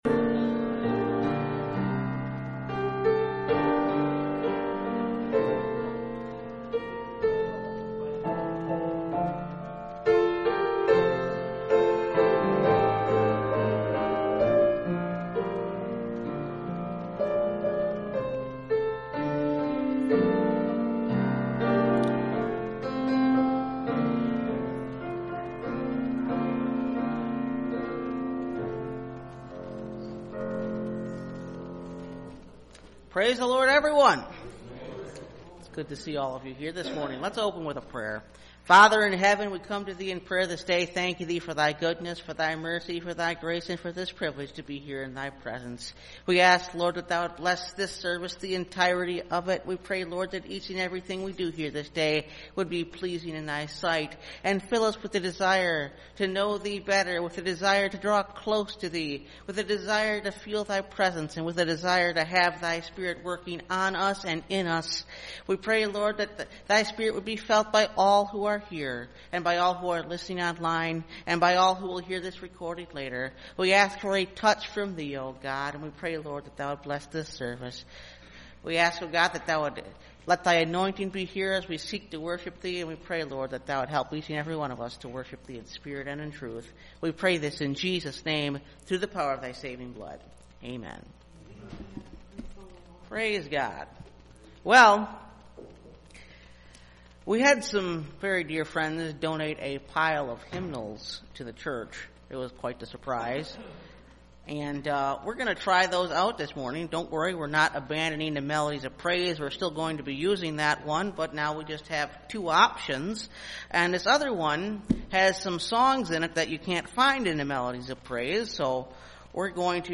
Take No Thought – Last Trumpet Ministries – Truth Tabernacle – Sermon Library